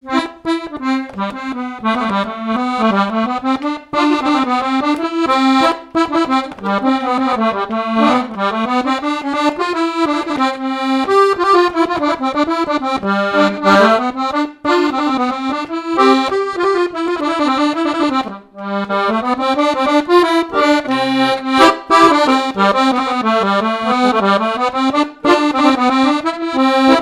danse : scottish
répertoire de chansons, et d'airs à danser
Pièce musicale inédite